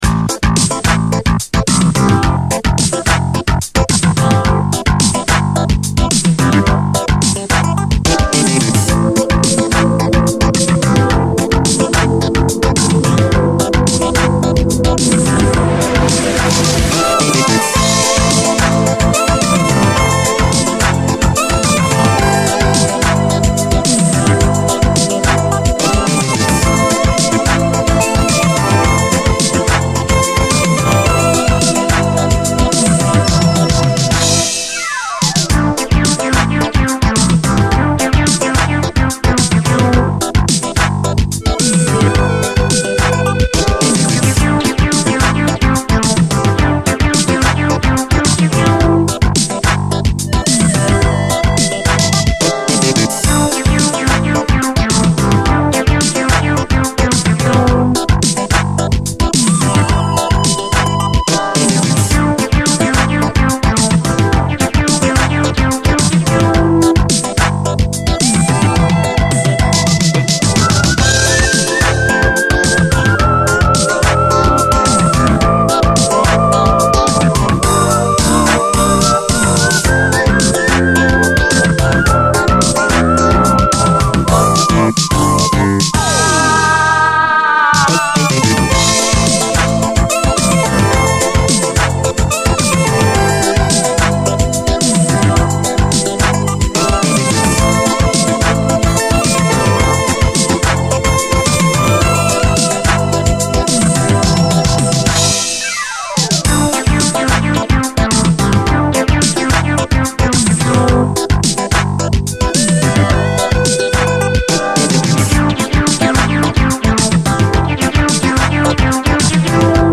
DISCO
メロディアスな美しい展開に心掴まれます